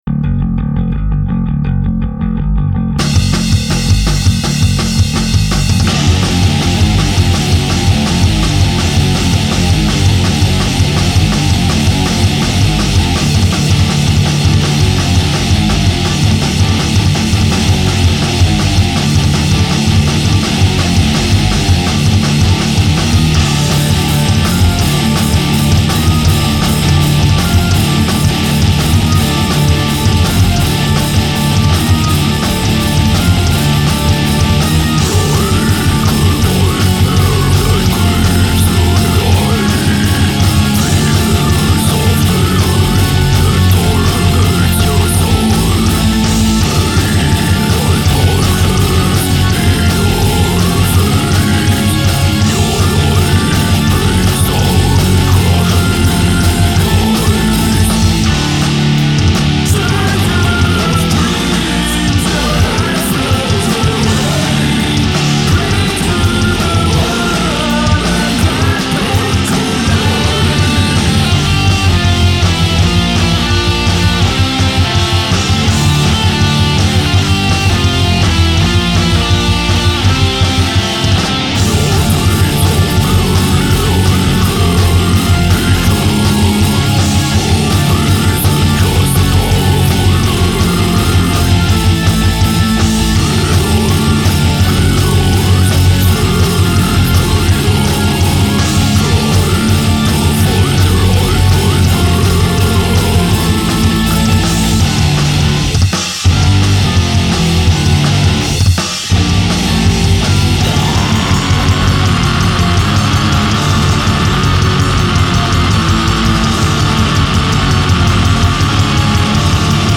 Melodic Death Metal, Death Metal, Thrash Metal